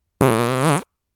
FART SOUND 40
Category 🤣 Funny